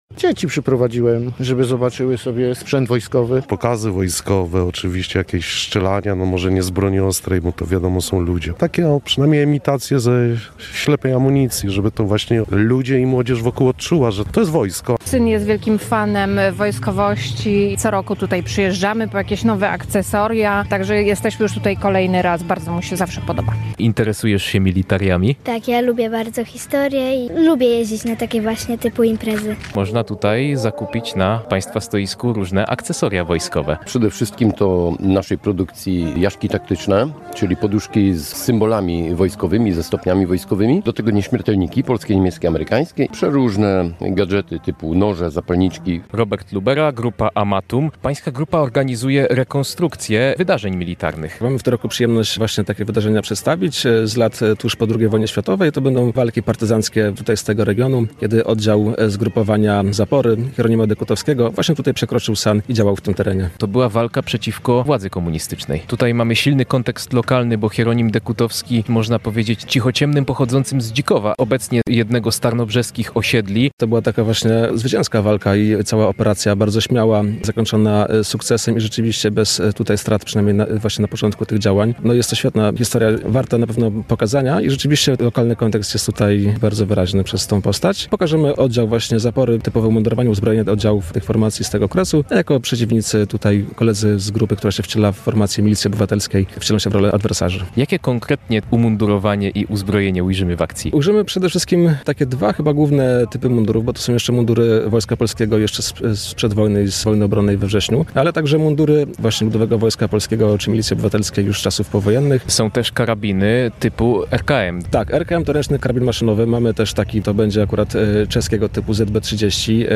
Mieszkańcy Nowej Dęby, Tarnobrzega, Rzeszowa, a także bardziej odległych regionów Polski, takich jak Tychy na Śląsku uczestniczą dziś (21.06) w wojskowo-muzycznej imprezie Militariada.